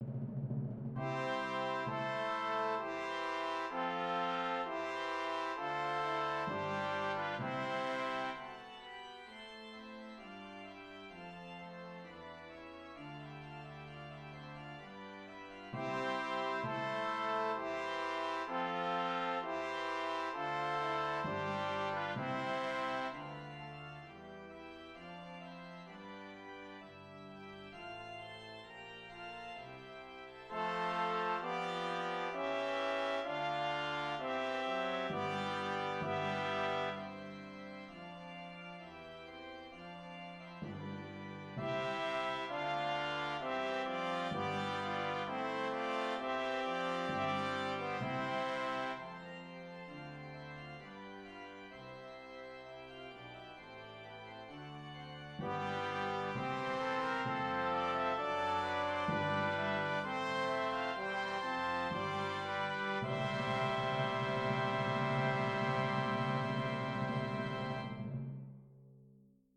Classical (View more Classical Brass Ensemble Music)